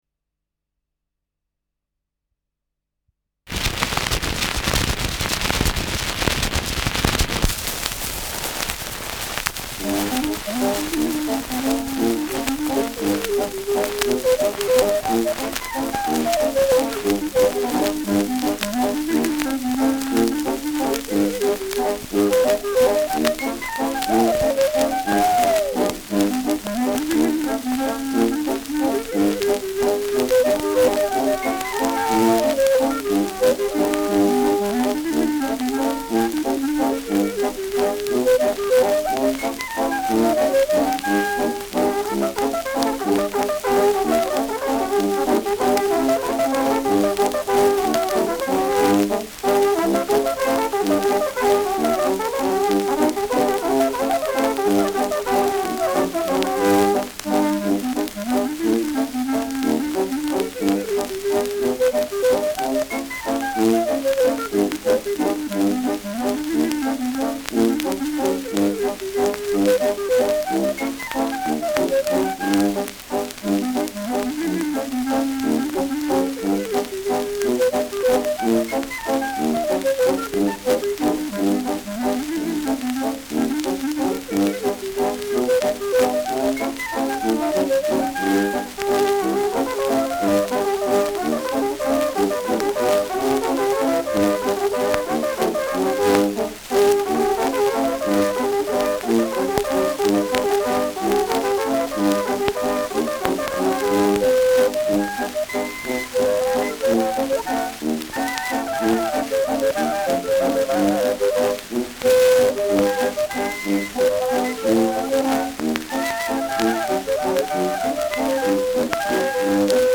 Schellackplatte
Abgespielt : Stärkeres Grundrauschen : Gelegentlich leichtes Knacken : Teils verzerrt : Am Ende stärker verrauscht
[vor 1. Weltkrieg]